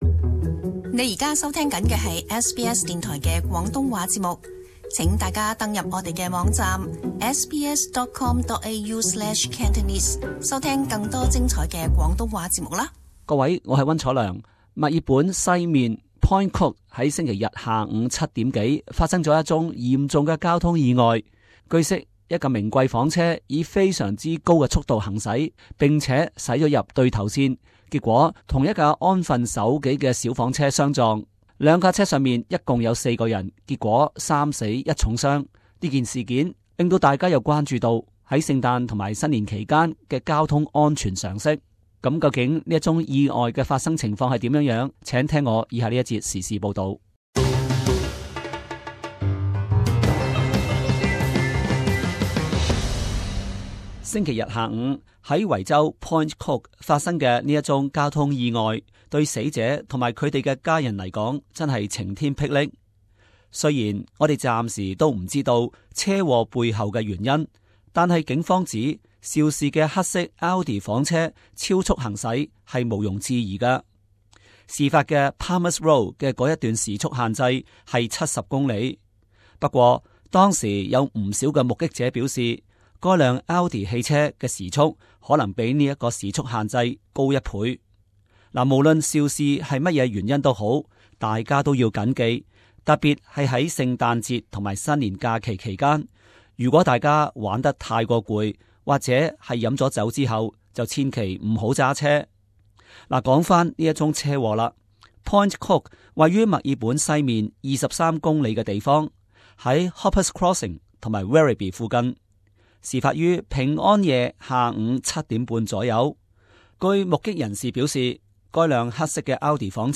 【時事報導】 墨爾本兩車相撞 3 死惹關注